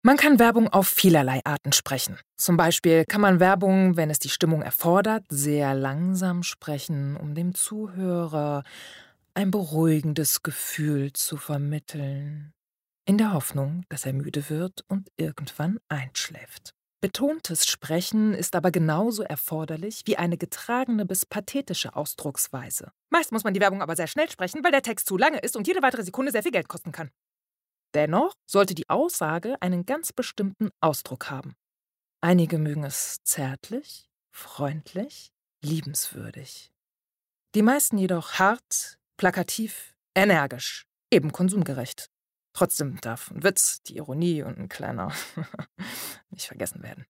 dunkel, sonor, souverän
Commercial (Werbung)